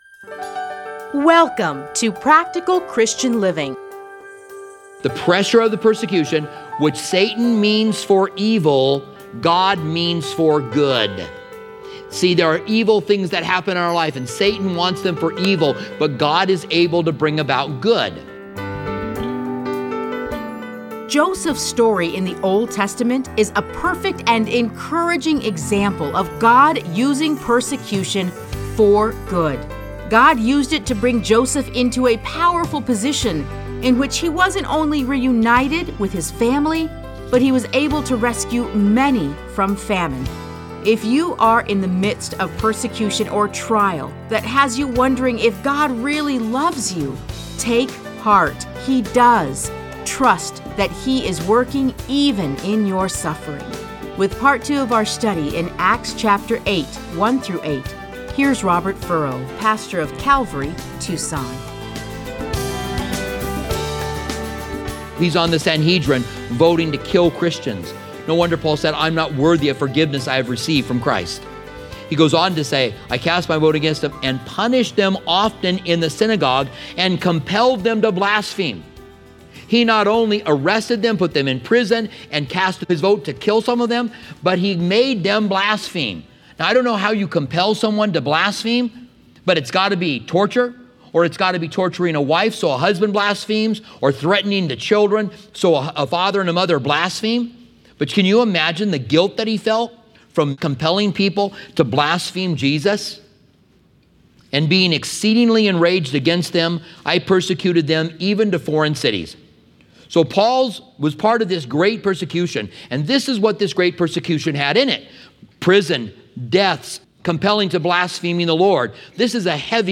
Listen to a teaching from Acts 8:1-8.